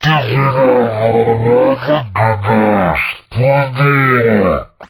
izlome_attack_2.ogg